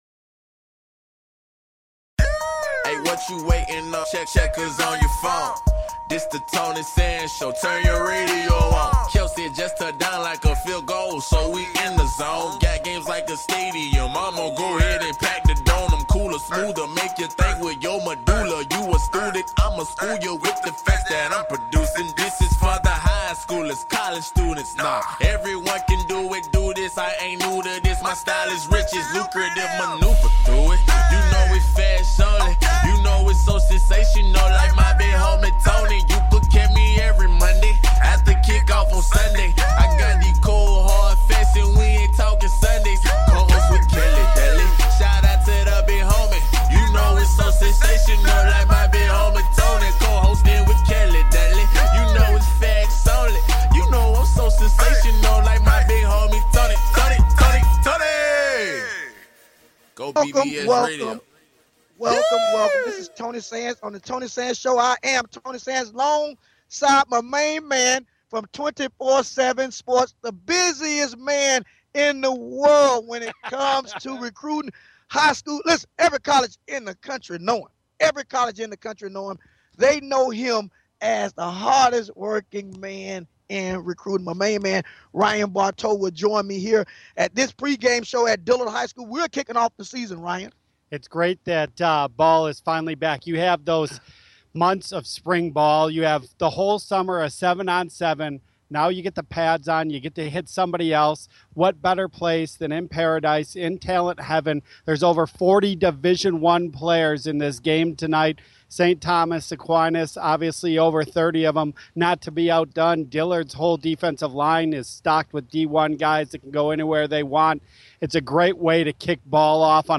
(LIVE ON LOCATION) - Scouting High School Football players
Talk Show